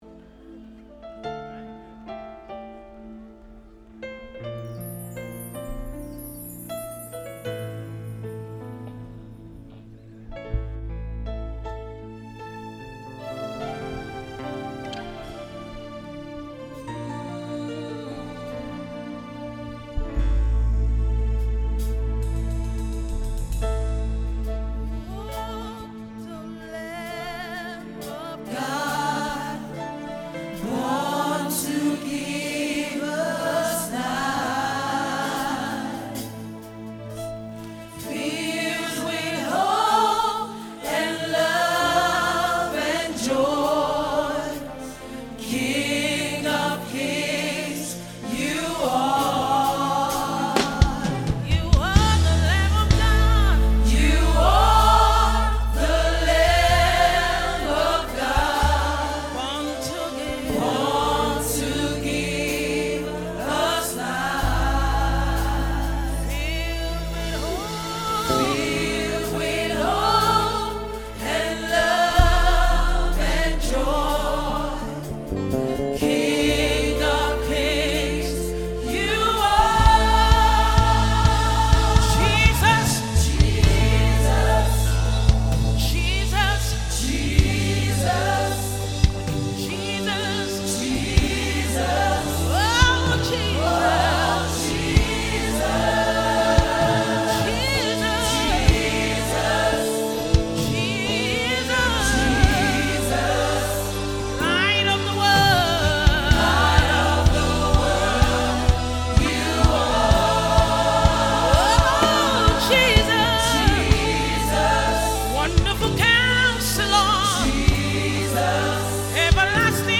Christmas Songs